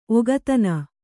♪ ogatana